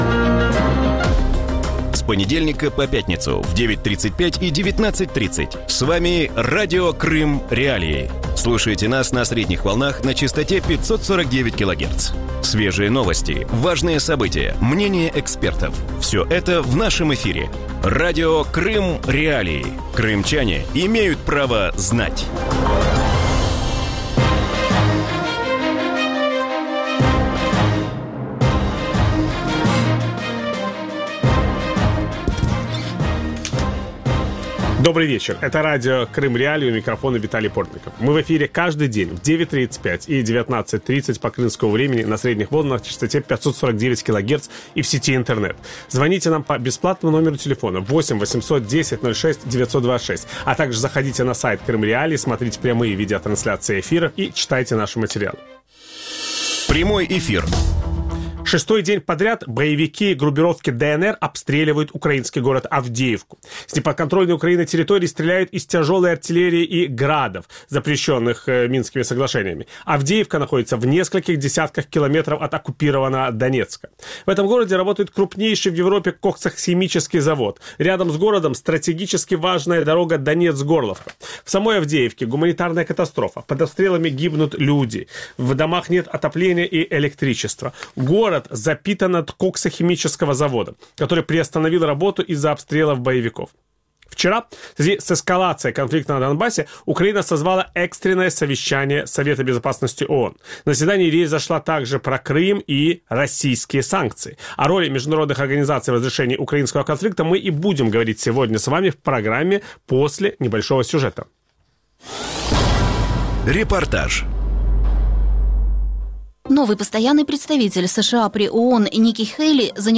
У вечірньому ефірі Радіо Крим.Реалії говорять про останнє засідання Ради безпеки ООН, скликане Україною у відповідь на ескалацію у районі Авдіївки. Чи може Організація об'єднаних націй запропонувати дієві інструменти для протидії російській агресії і яка доля очікує ООН із приходом нової адміністрації президента США? На ці питання дадуть відповідь екс-міністр закордонних справ України Володимир Огризко і російський політолог Дмитро Орєшкін. Ведучий програми – Віталій Портников.